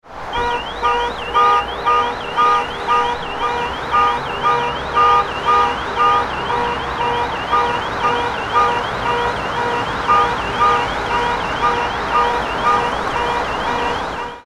Sound Effects / Street Sounds 17 Jul, 2025 Car Alarm Sound Effect On A Noisy Street Read more & Download...
Car-alarm-sound-effect-on-a-noisy-street.mp3